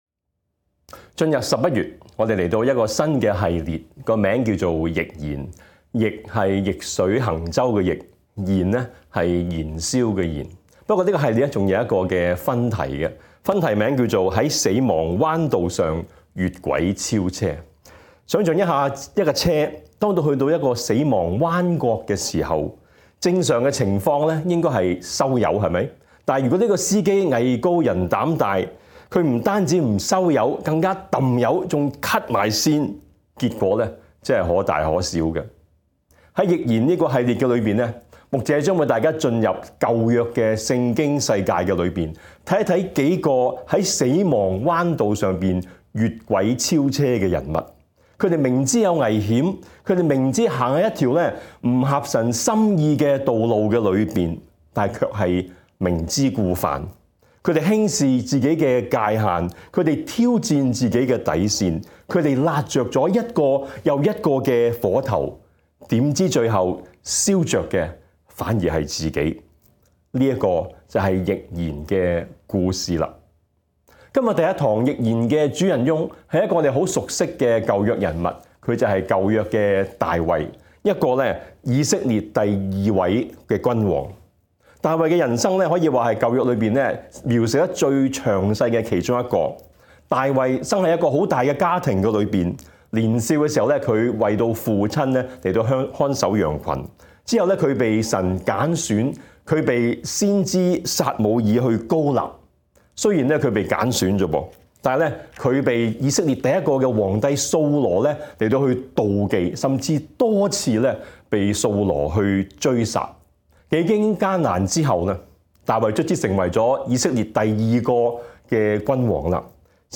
逆燃 新講道系列主題是 “逆燃”，分題為...